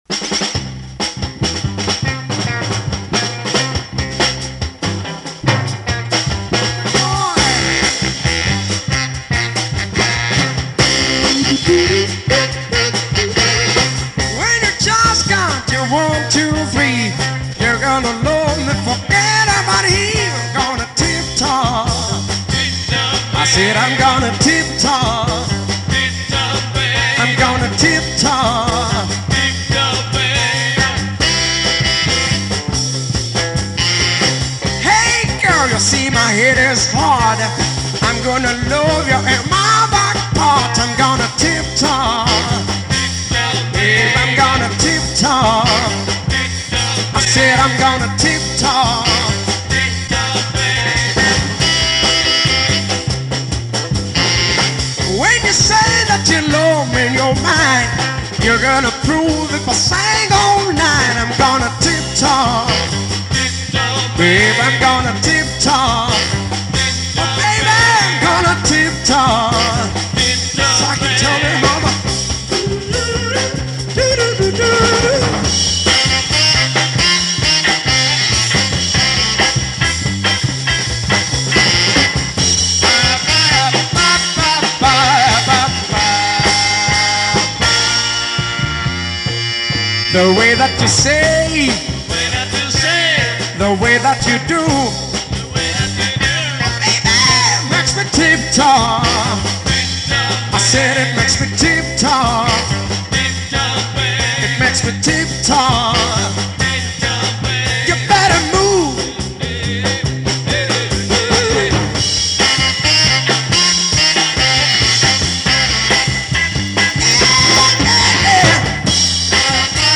Bass & Vocals
Drums & Vocals
Guitars & Vocals
Saxophones & Vocals